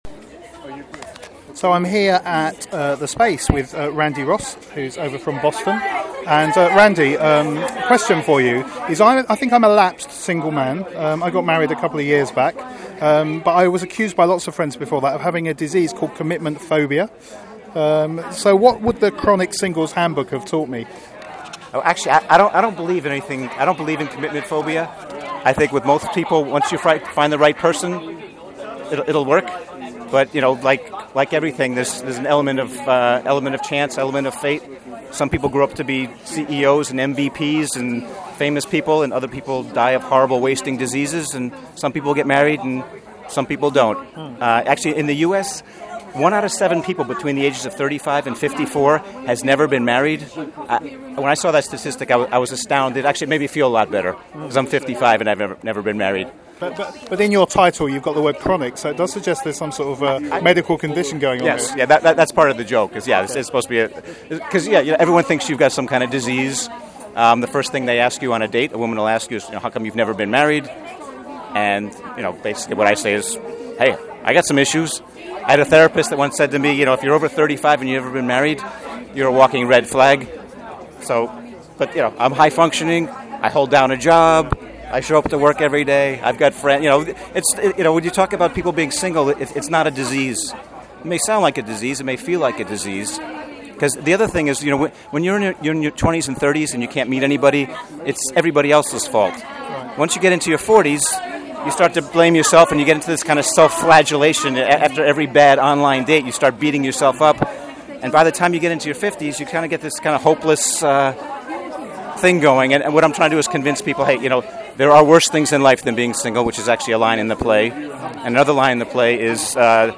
Edinburgh Audio 2014